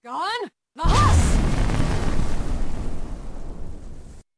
drainvitalityfemaleshout.mp3